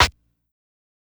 SNARE_FPROG.wav